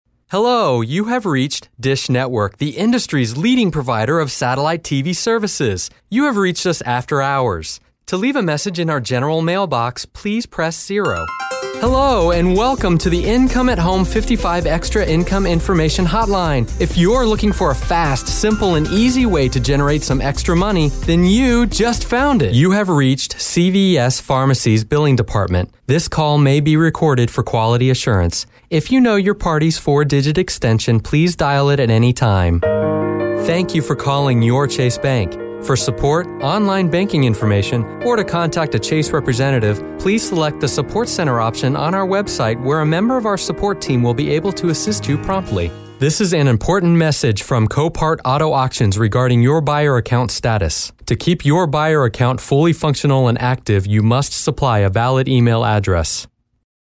IVR
English - USA and Canada
Phone System/IVR